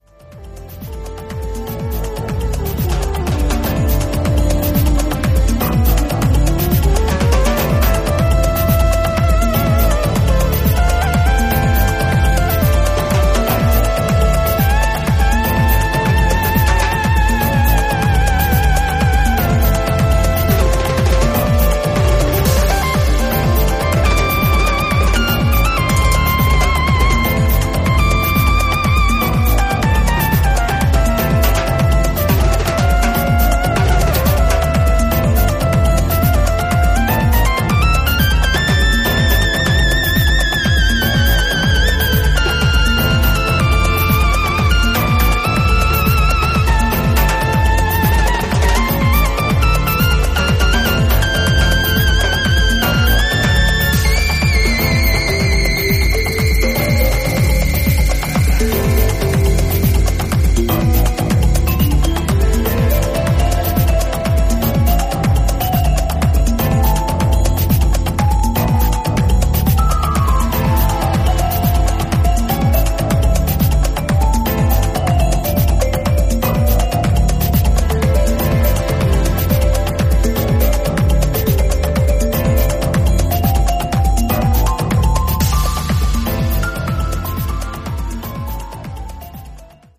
It’s real house music.